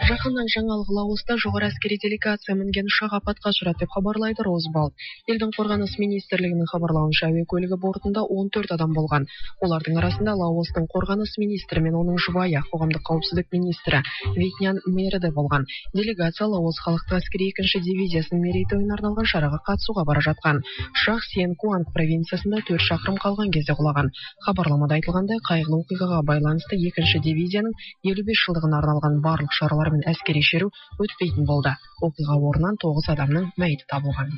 The speaker is very fluent, so it’s unlikely to be something learned in adulthood, i.e. it’s not like the obvious French accent one hears in Provençal.
Also, these loanwords are pronounced naturally (for Russian), so I’d even guess that the speaker might be bilingual with Russian as the second (or first) language.
18 May 2014 at 2:23 pm The speed of the speaker and the sound compression don’t helpbut I wonder is this a Tatar dialect perhaps from Crimea.